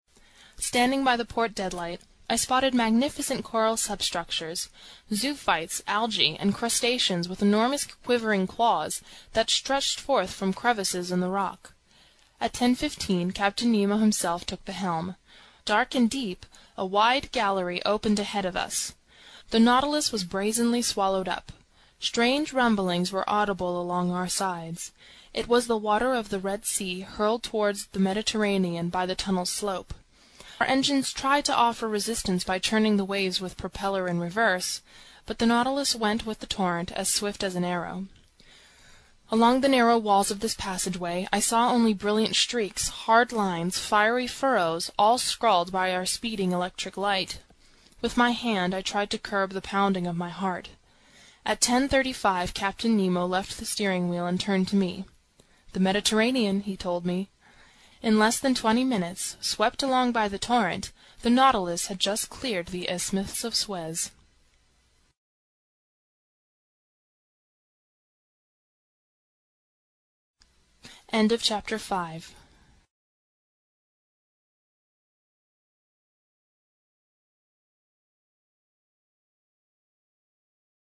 英语听书《海底两万里》第366期 第23章 珊瑚王国(64) 听力文件下载—在线英语听力室